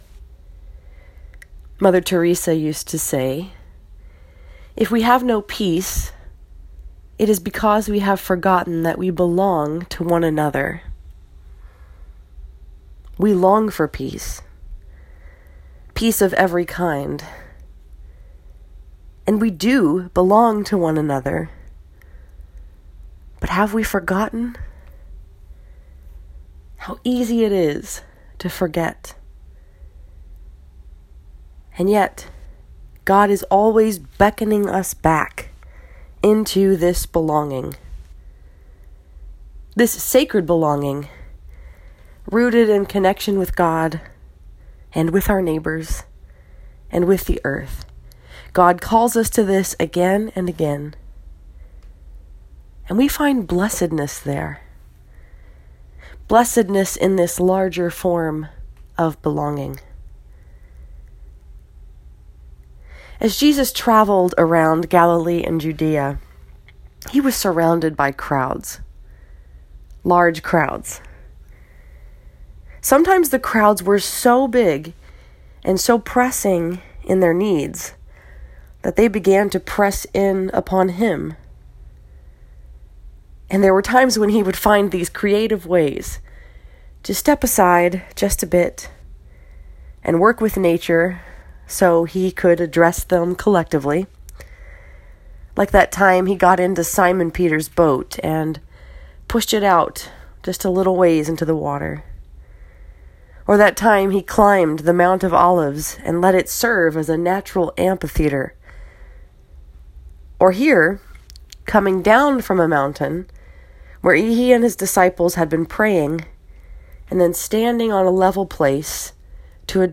This sermon was preached at Northside Presbyterian Church in Ann Arbor, MI and was focused upon Luke 6:17-26.